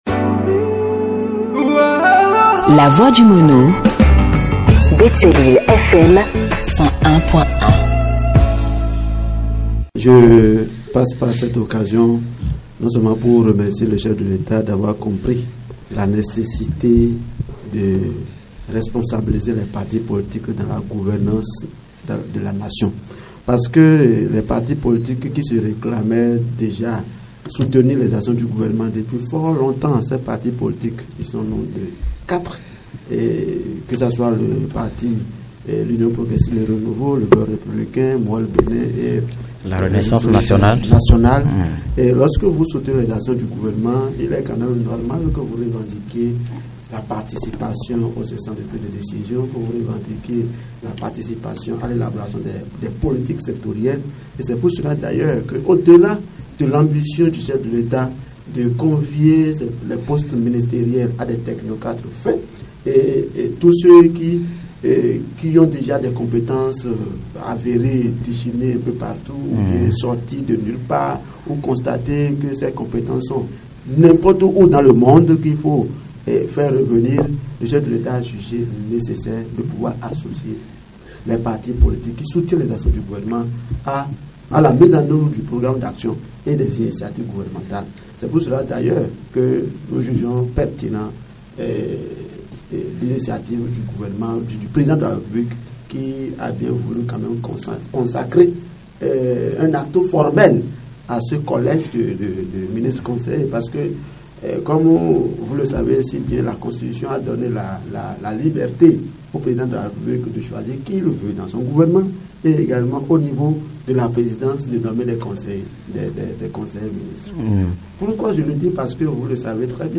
Reçu ce samedi 14 décembre 2024 sur l’émission Sans Tabou de Radio Betsaleel FM, Dr Cyrille Mahoussi Adankanhoundé, expert en gouvernance locale et deuxième adjoint au maire de la commune d’Athiémé, a remercié le président Talon qui a pris en compte cette doléance formulée par les partis politiques qui soutiennent ses actions. Pour lui, ces ministres conseillers vont contribuer à l’élaboration des politiques sectorielles. Voici un extrait des propos du deuxième adjoint au maire de la commune d’Athiémé